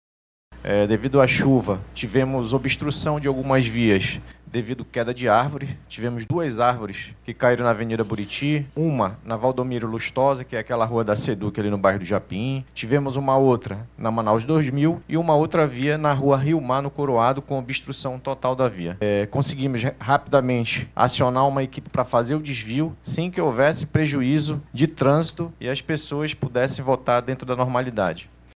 De acordo com os números divulgados, durante a coletiva de imprensa, nas primeiras cinco horas do pleito, 20 urnas apresentaram problemas, sendo que 11 precisaram ser substituídas.
O diretor do Instituto Municipal de Mobilidade Urbana, Stanley Ventilari, explicou que o órgão promoveu o reforço do efetivo para o pleito deste domingo, com mais de 350 agentes de trânsito distribuídos em todas as regiões da cidade.